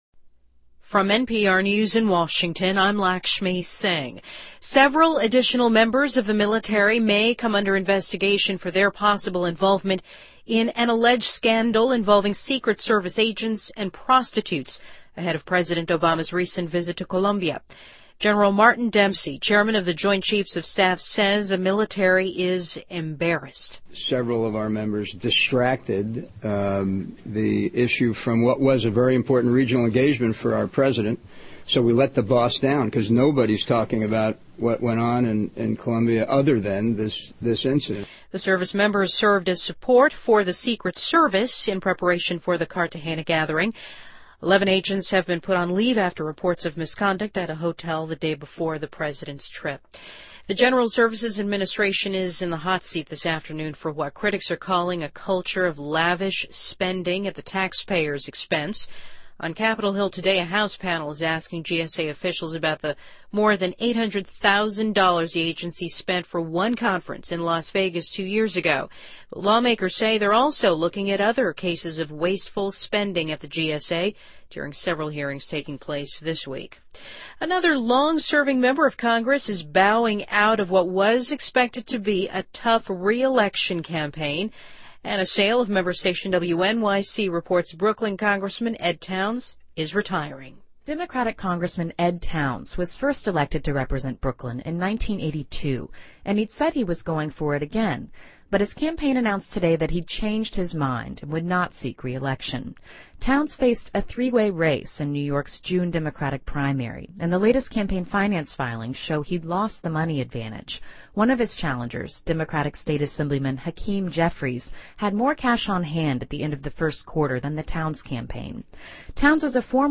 NPR News,美国商务部指出美国上个月的零售业销售额有所上升